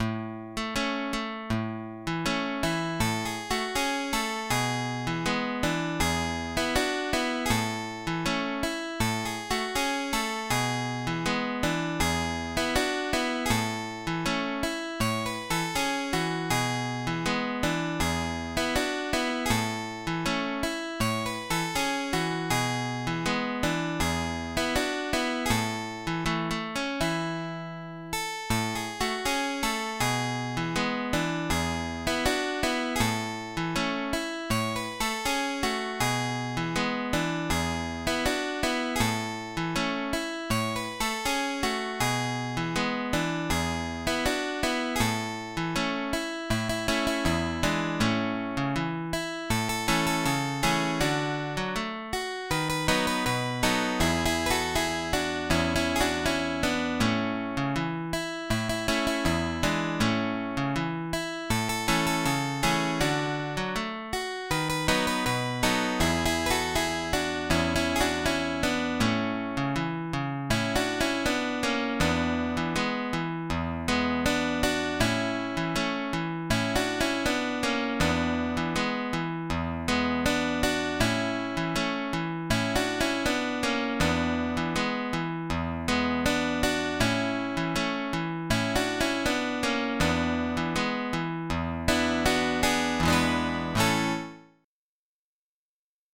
Habanera